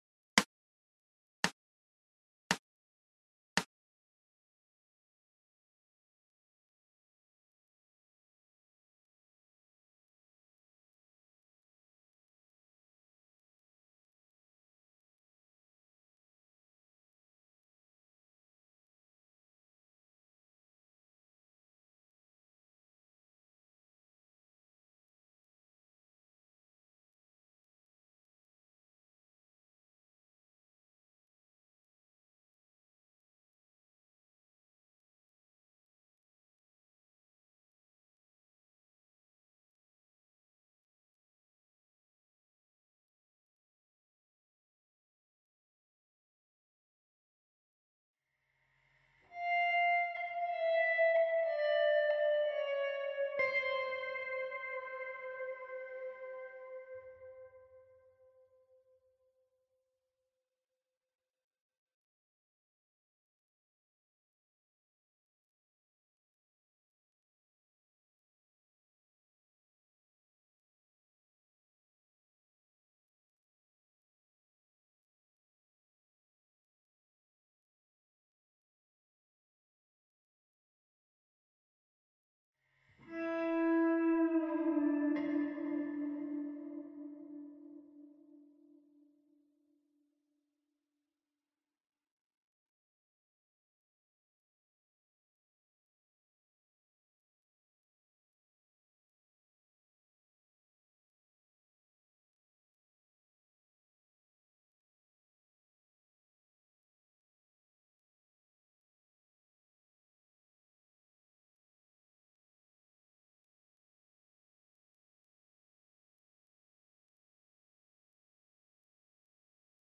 GUITARRA ELECTRICA ARREGLOS (Descargar)